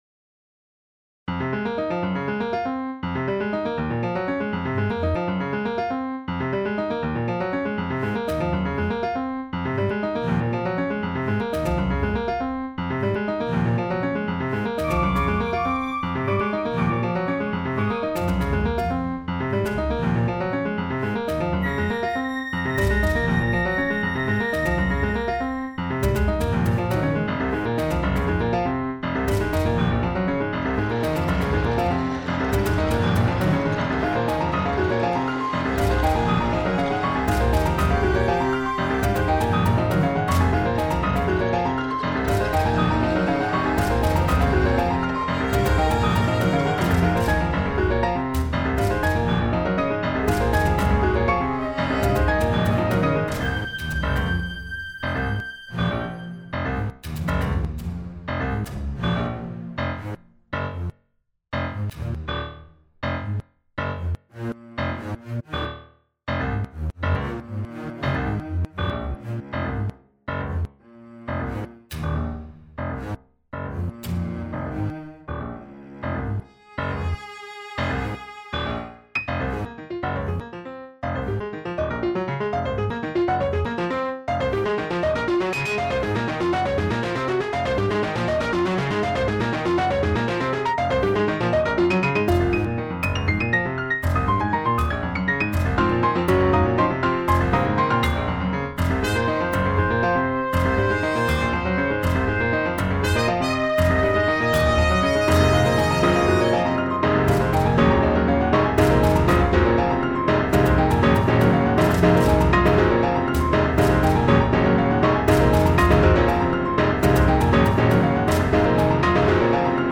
Contrabass x Cello x Piano x Flute x Trumpet... and Strings